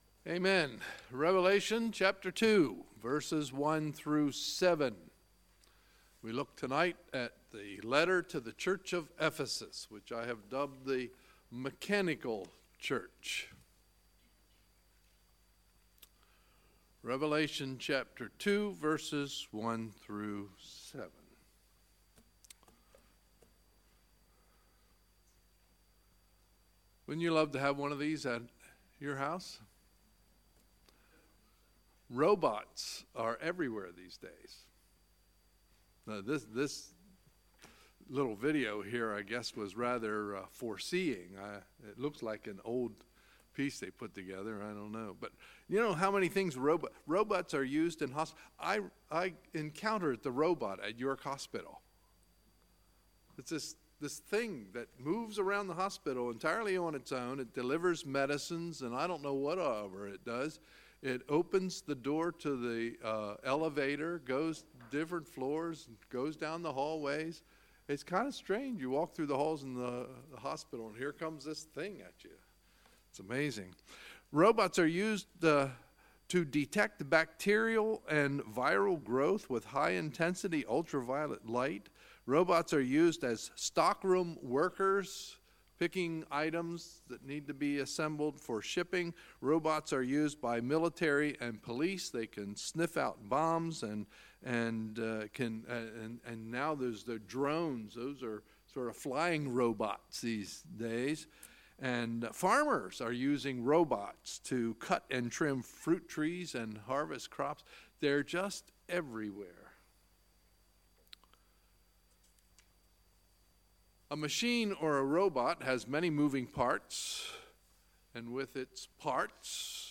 Sunday, February 18, 2018 – Sunday Evening Service